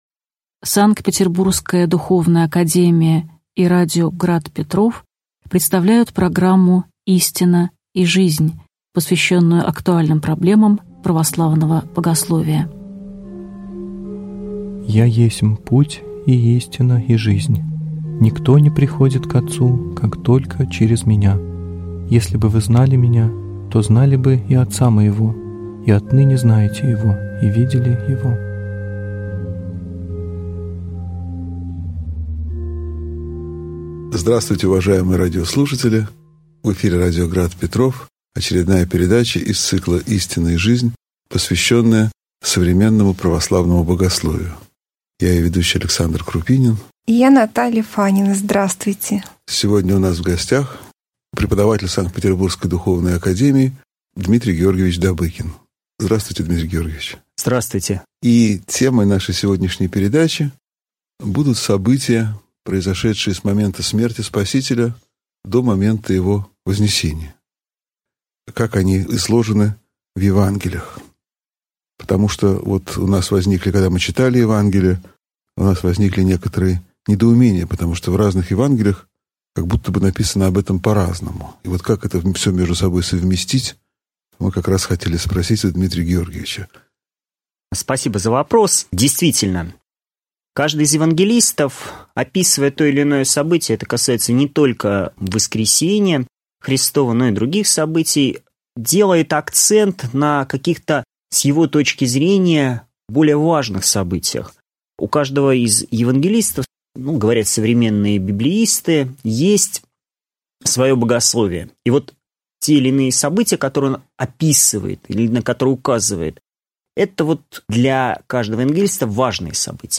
Аудиокнига Воскресение Христово (часть 2) | Библиотека аудиокниг